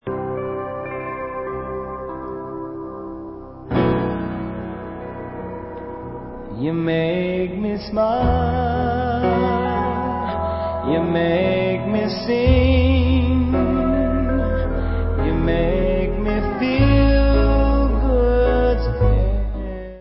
sledovat novinky v oddělení Dance/Soul